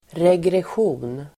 Ladda ner uttalet
regression.mp3